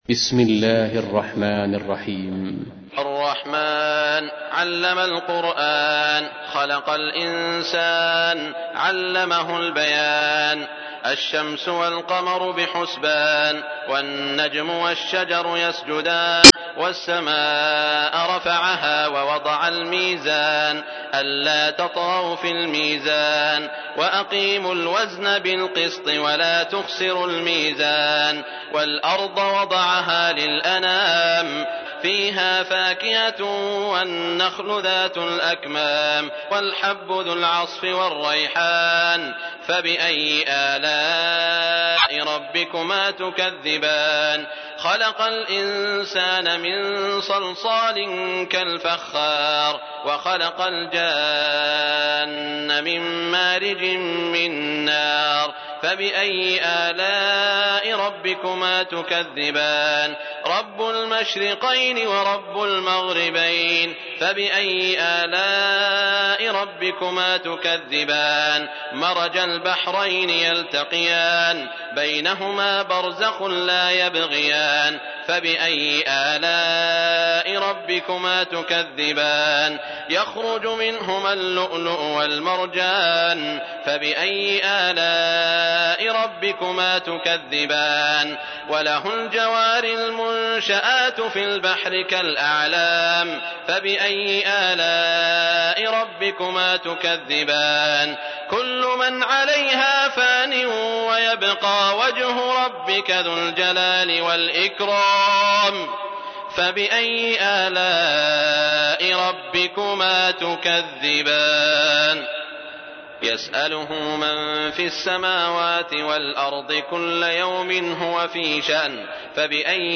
تحميل : 55. سورة الرحمن / القارئ سعود الشريم / القرآن الكريم / موقع يا حسين